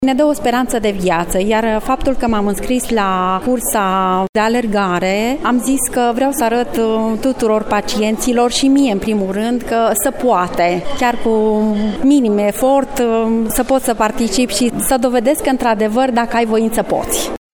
alergător: